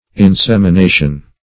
Insemination \In*sem`i*na"tion\, n.